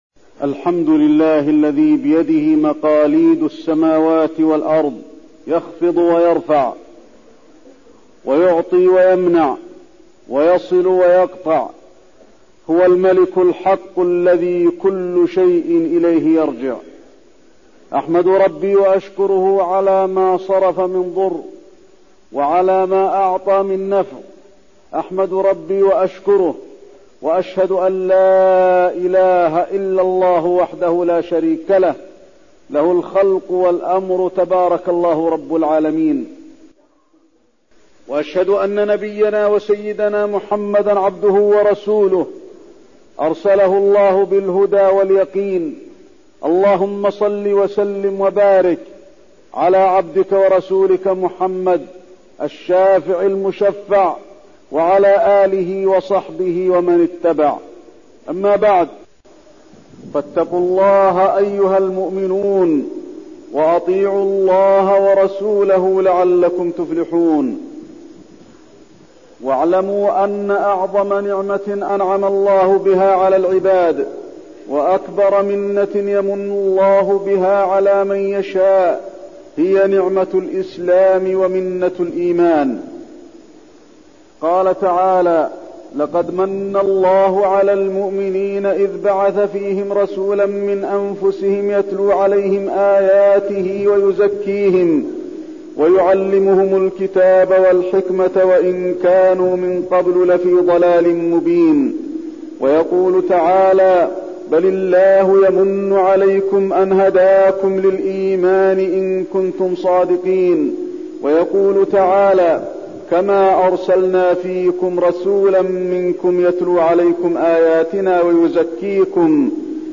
تاريخ النشر ٥ شعبان ١٤٠٧ المكان: المسجد النبوي الشيخ: فضيلة الشيخ د. علي بن عبدالرحمن الحذيفي فضيلة الشيخ د. علي بن عبدالرحمن الحذيفي كيفية الدعوة إلى الله